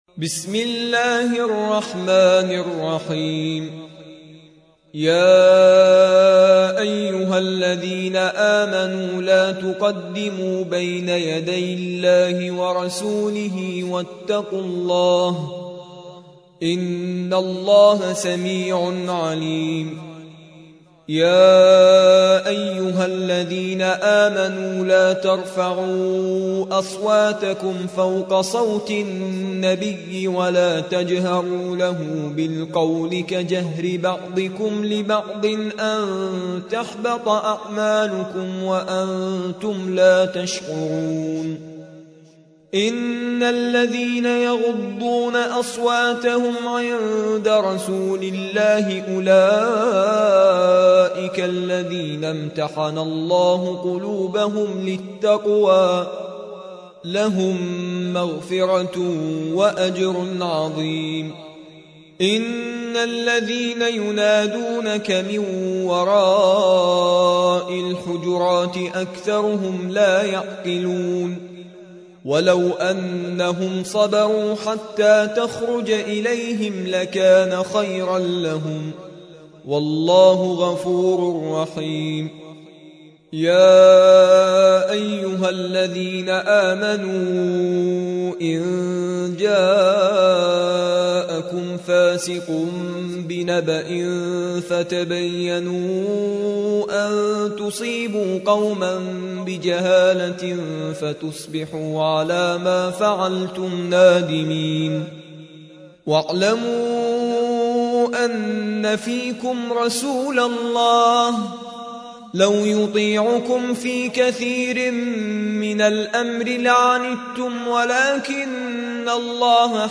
49. سورة الحجرات / القارئ